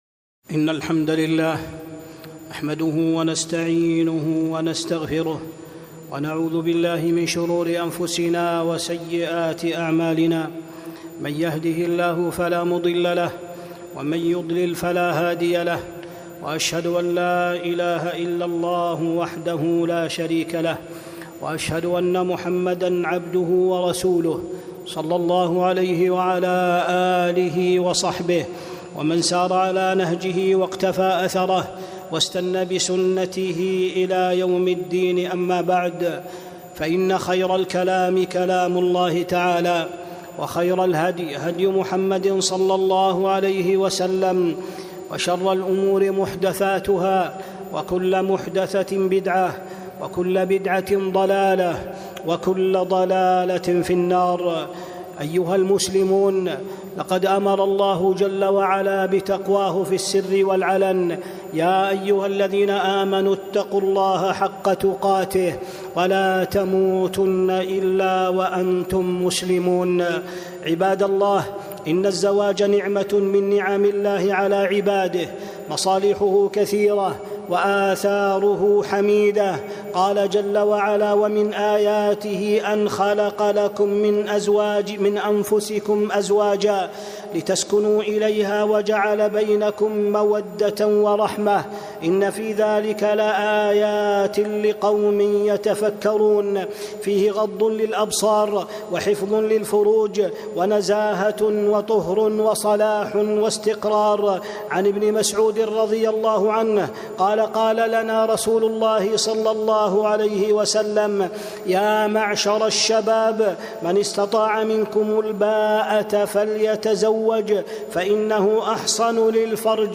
خطبة - استقرار الأسرة
ألقيت يوم الجمعة ٢٢ ذي الحجة ١٤٤٥ في مسجد قباء